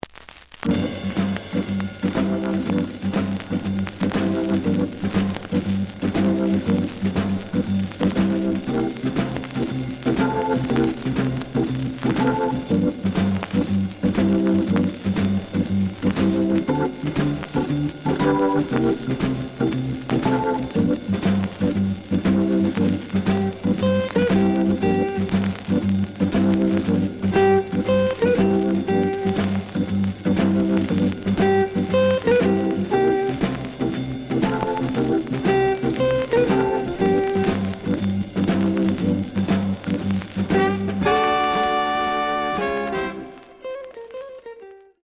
The audio sample is recorded from the actual item.
●Genre: Jazz Funk / Soul Jazz
Some noise on A side.)